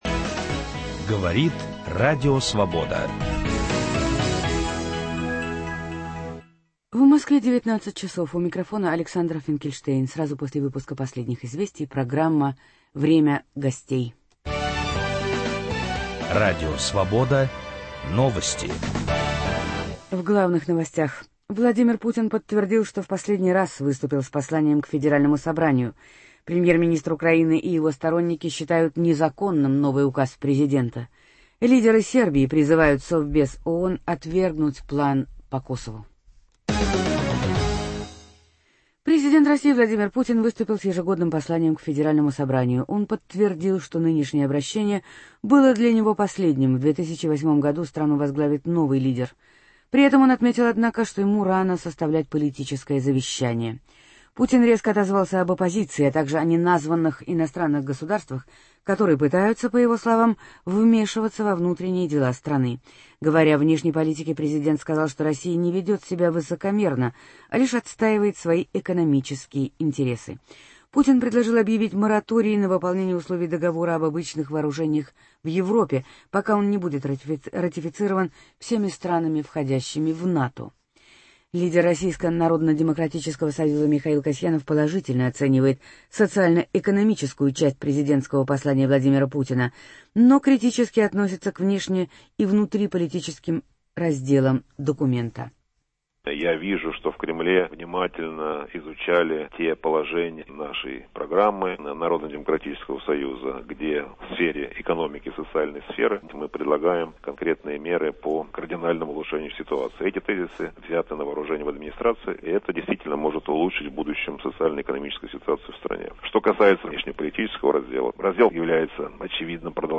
27 апреля в Москве и Мытищах начинается чемпионат мира по хоккею. В программу «Время гостей» приглашен двукратный олимпийский чемпион и чемпион мира по хоккею Александр Кожевников.